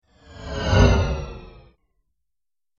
دانلود صدای ماشین 21 از ساعد نیوز با لینک مستقیم و کیفیت بالا
جلوه های صوتی